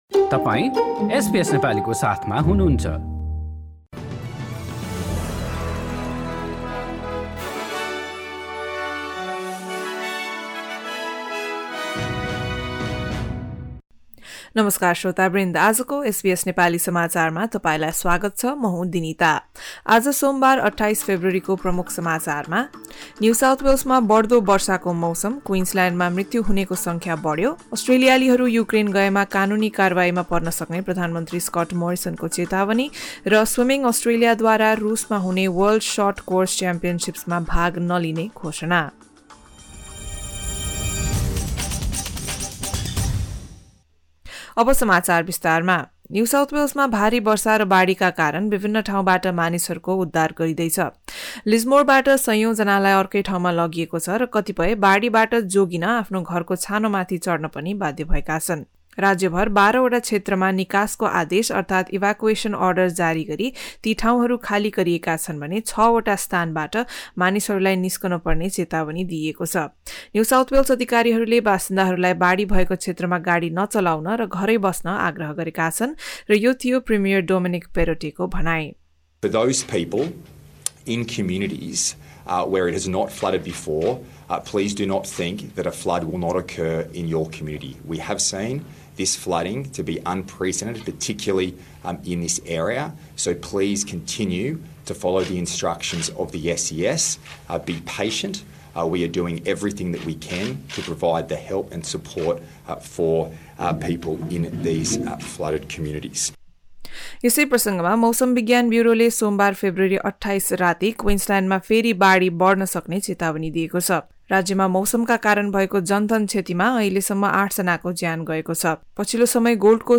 एसबीएस नेपाली अस्ट्रेलिया समाचार: सोमबार २८ फेब्रुअरी २०२२